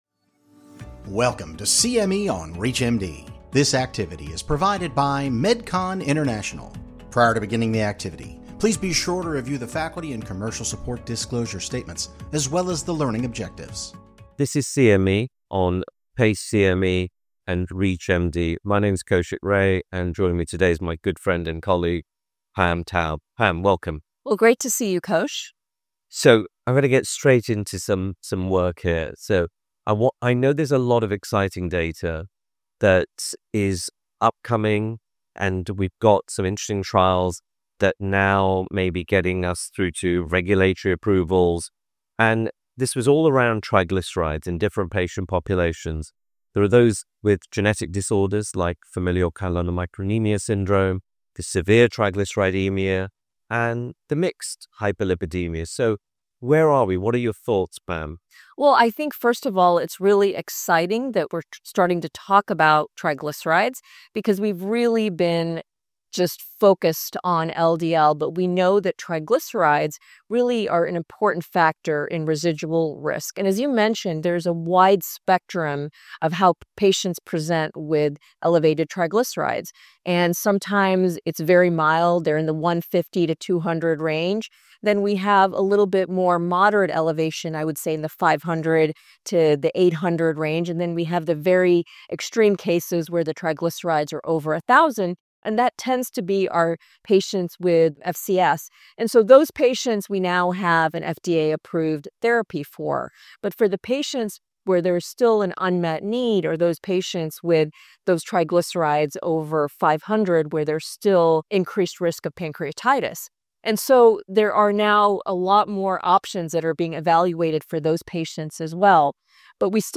Recent developments on APOC3 inhibition show promising results for the management of these patient groups. Four experts discuss the characteristics, diagnostic criteria and the risks of patients with FCS, SHTG, and mixed hyperlipidemia, and review the recent evidence of clinical trials with new APOC3 inhibitors.